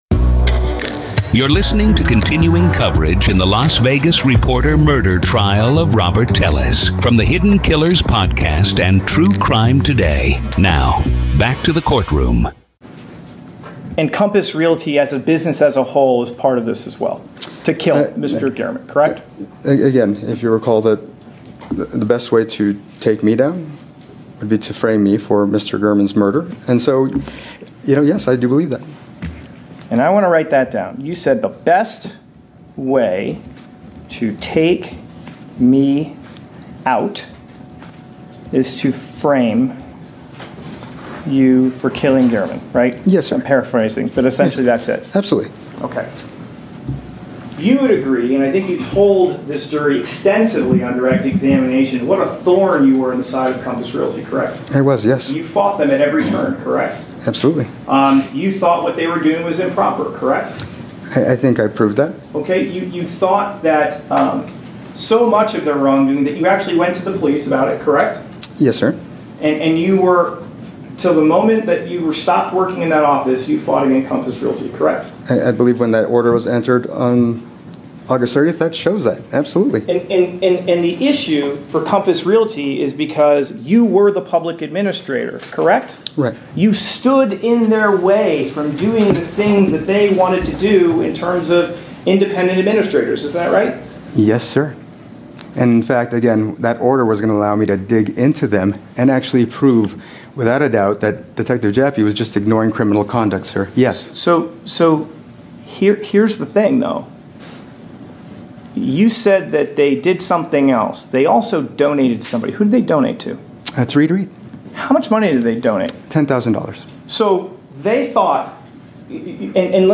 Telles Takes the Stand-Raw Court Audio-NEVADA v. Robert Telles DAY 7 Part 3